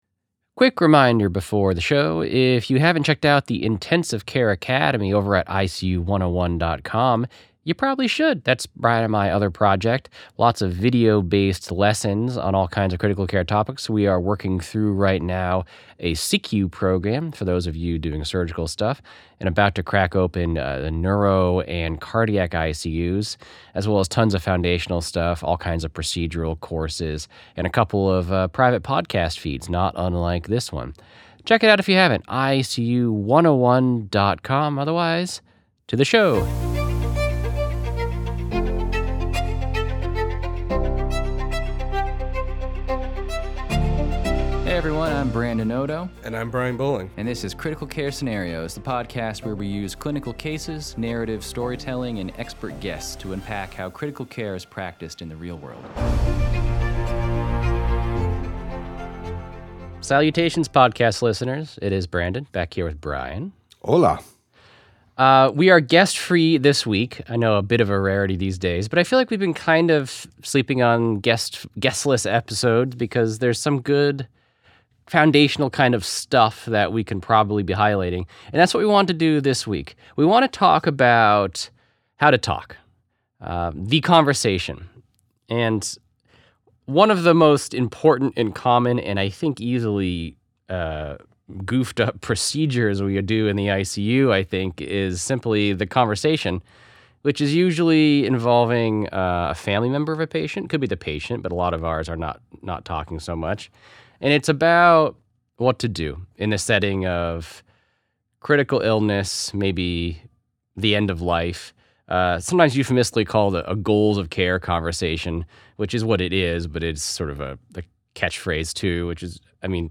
Episode 91: A simulated goals of care conversation – Critical Care Scenarios